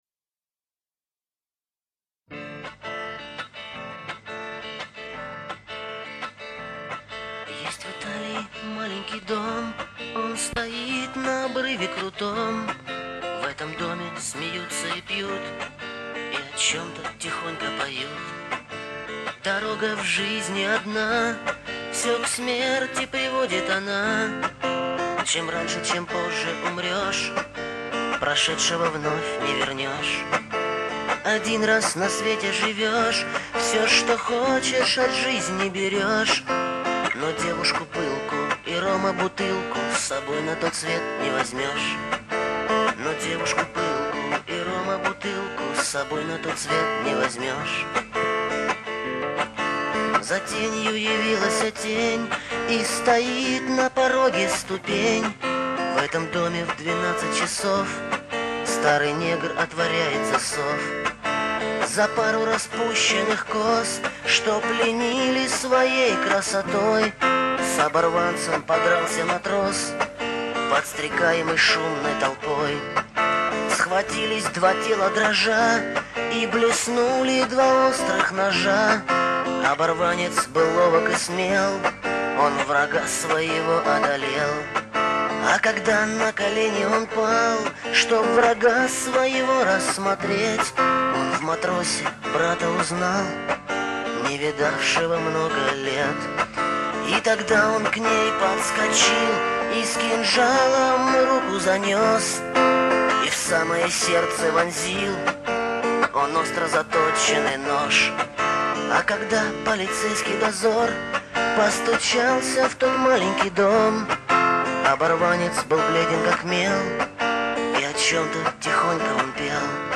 Гитара / Дворовые